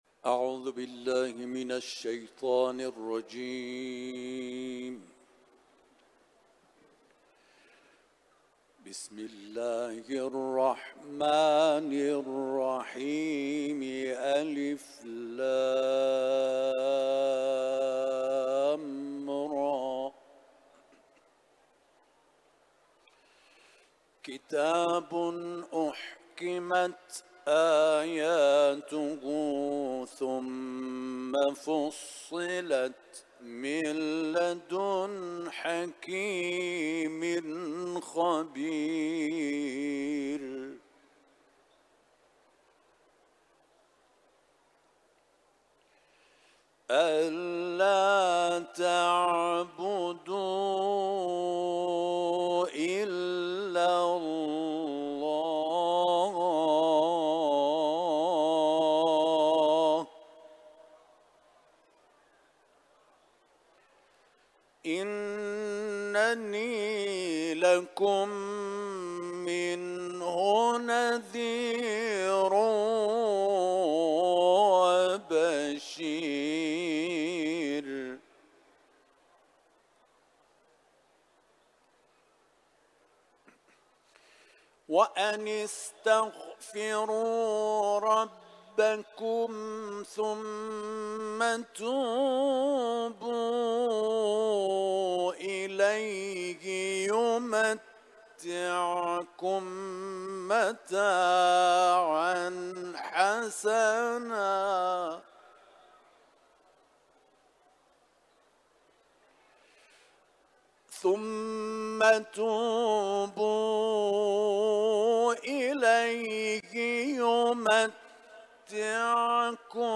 تلاوت قرآن ، سوره هود